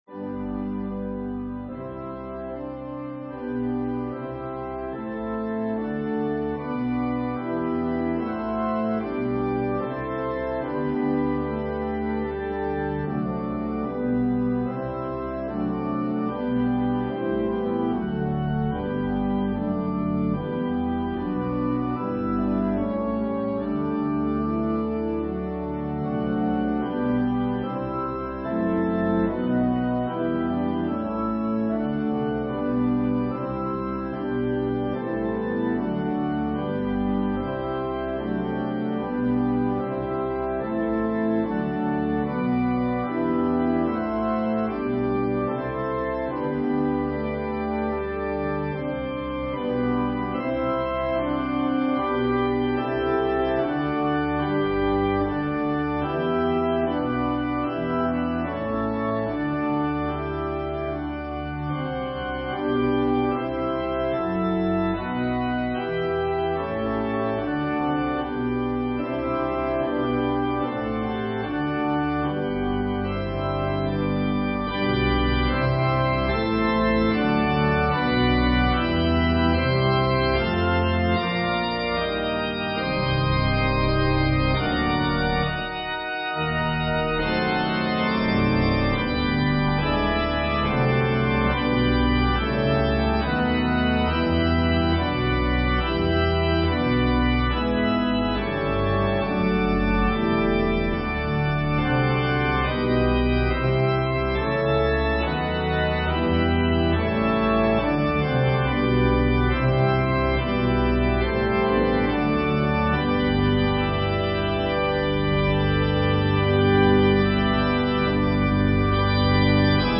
An organ solo version